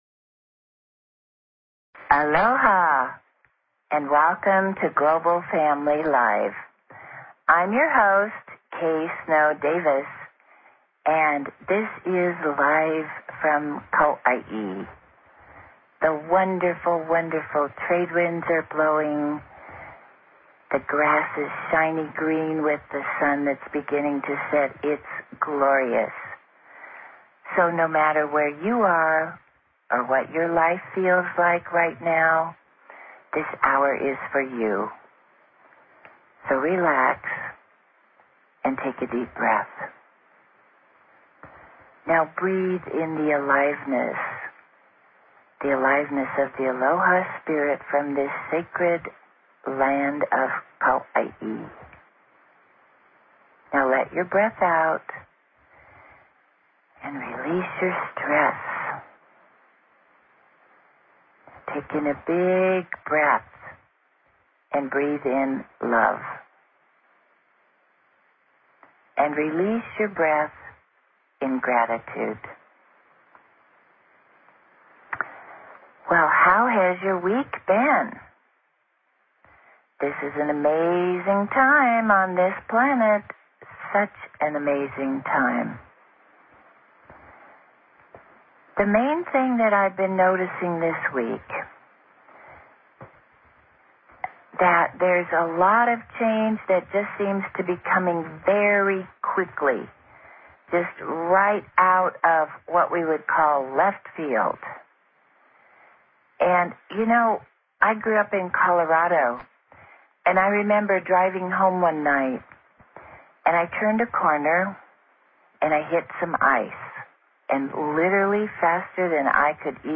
Talk Show Episode, Audio Podcast, Global_Family_Live and Courtesy of BBS Radio on , show guests , about , categorized as
Live from Kau'ai, HI